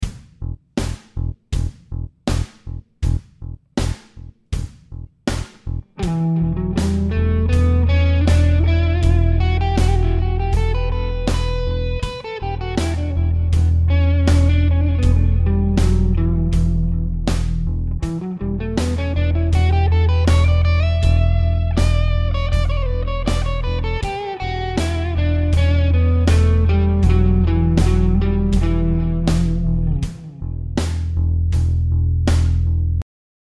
The bass is just playing the E note in all examples.
Dorian
This has a much darker sound to it.
The only difference is that we are not focussing the note on D but E. It does not sounds bright and cheerful like we would expect any major scale to, it sounds completely different from major.